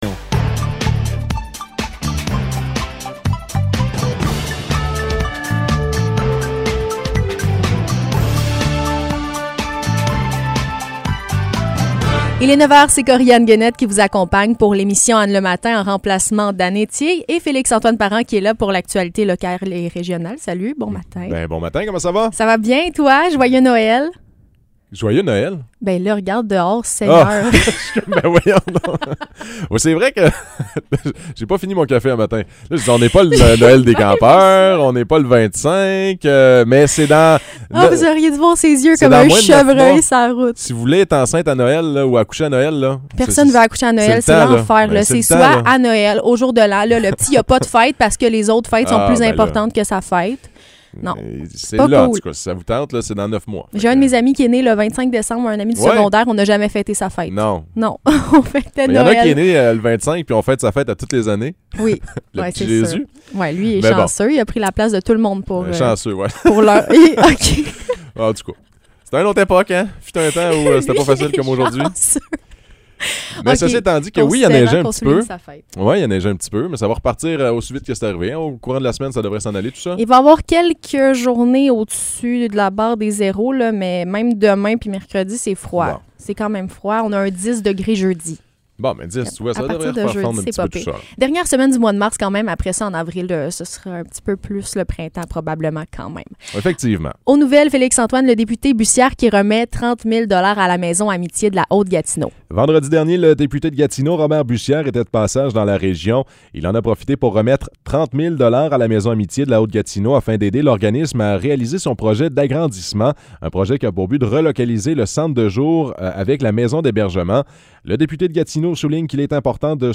Nouvelles locales - 28 mars 2022 - 9 h